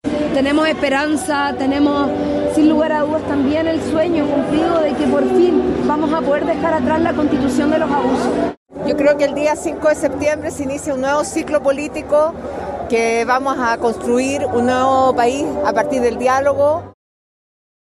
Cerca de medio millón de personas -según los organizadores- se congregaron en la Alameda con Santa Rosa, en el centro de Santiago, con motivo del cierre de la campaña del comando del Apruebo.
La vocera del Apruebo, la diputada Karol Cariola, resaltó los principales puntos de la propuesta de nueva Constitución, indicando que permite dejar atrás la actual Carta Magna.
Mientras que la presidenta del Partido Socialista, Paula Vodanovic, aseguró que desde la próxima semana se debe trabajar en modificaciones, pues iniciará una nuevo época.